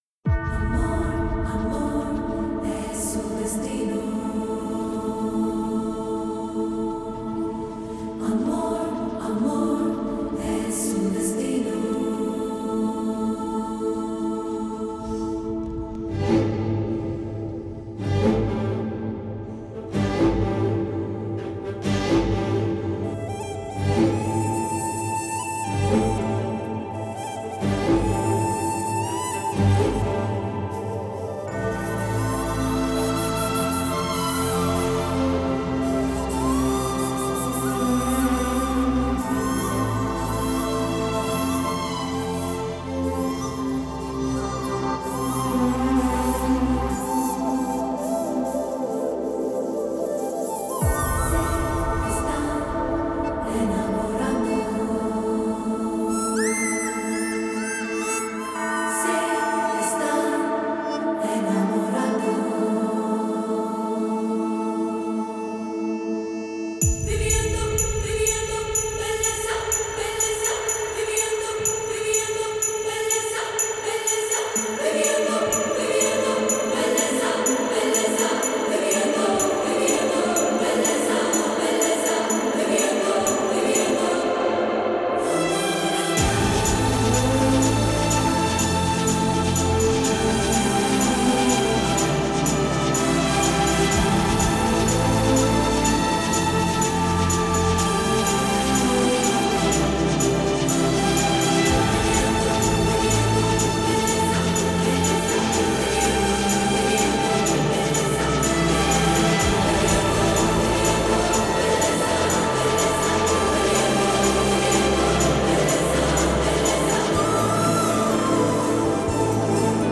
in HD quality, without disturbing sounds and dialogues